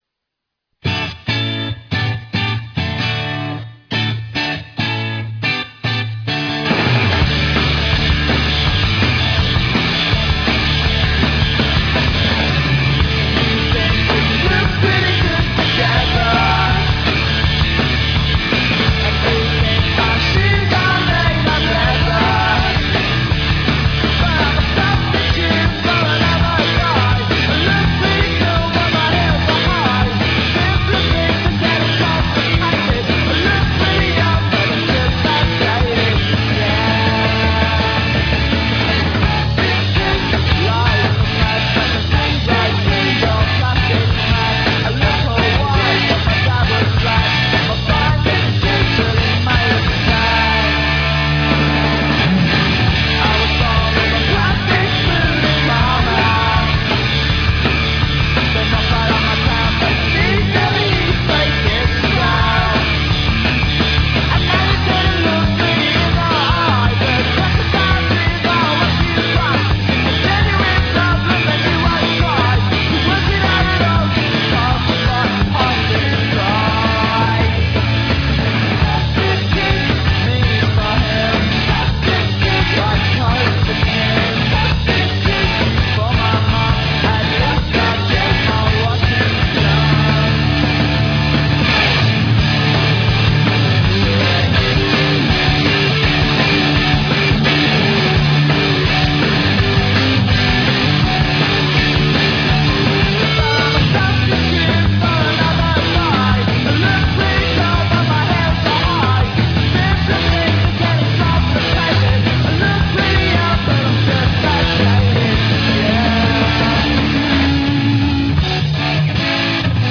The ensuing performance is incriminatingly reckless.